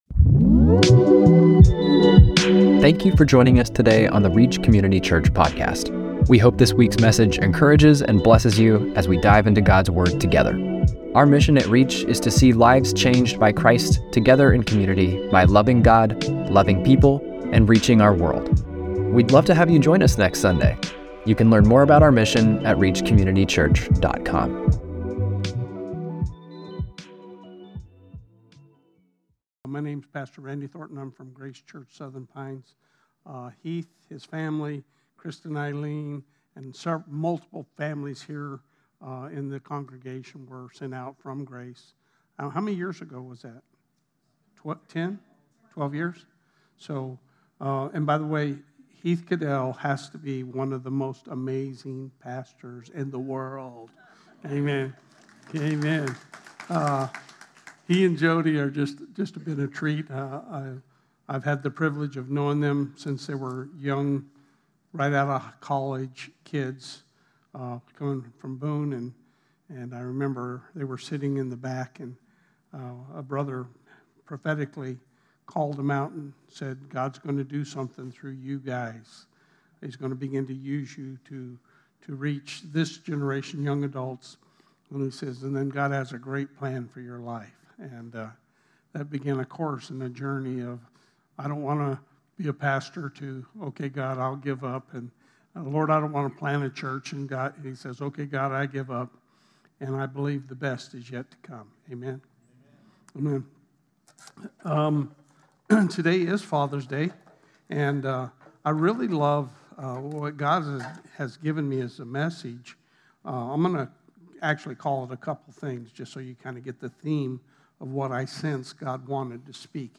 6-15-25-Sermon.mp3